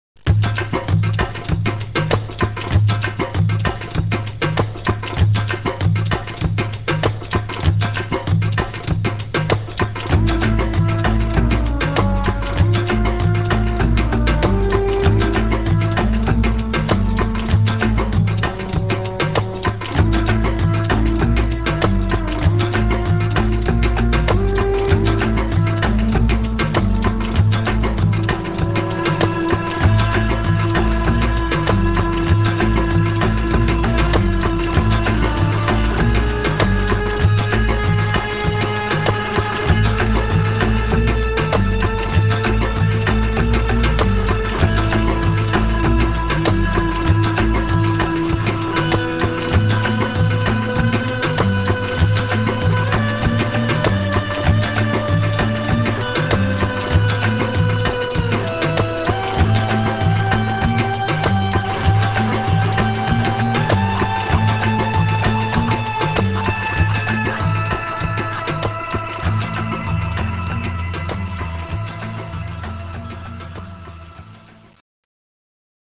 194 kB MONO
(inst.)